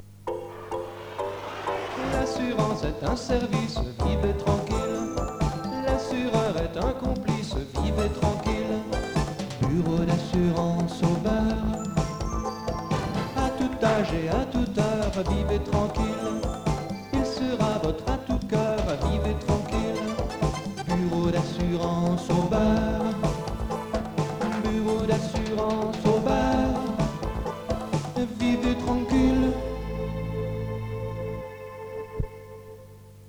Baa à la radio !